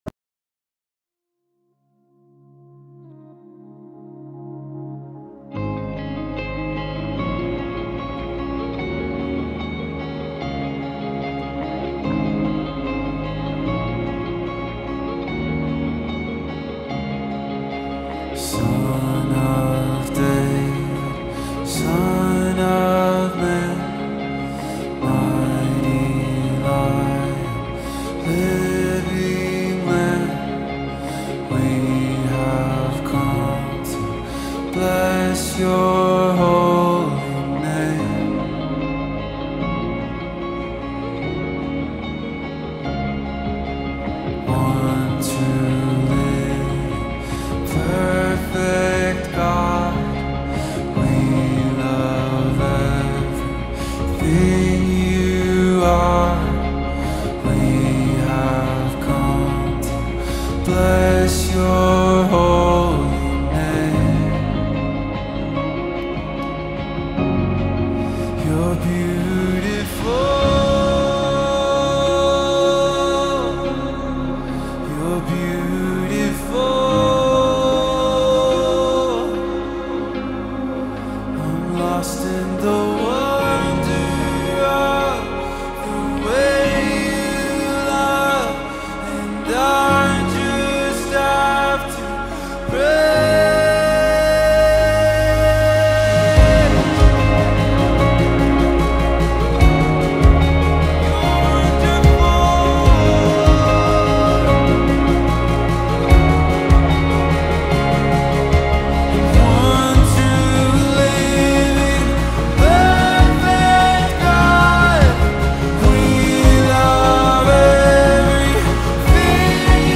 песня
203 просмотра 351 прослушиваний 35 скачиваний BPM: 74